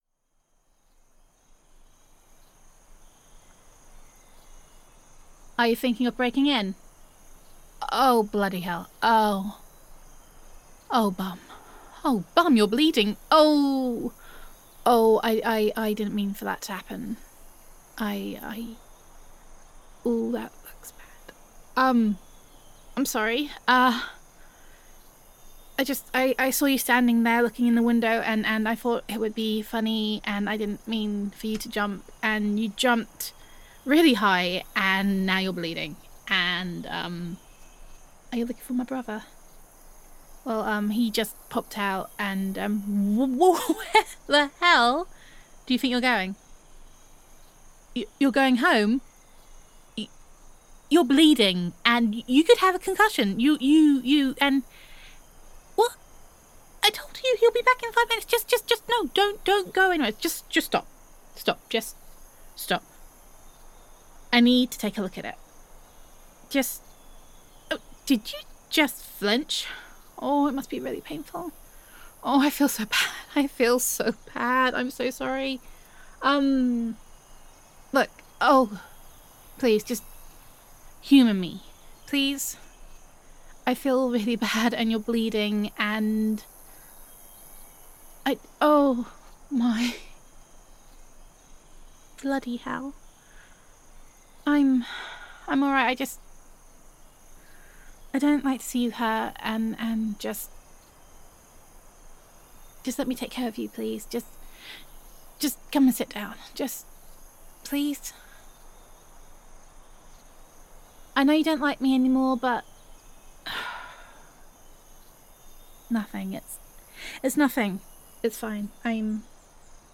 [F4A] My Brother’s Idiot Friend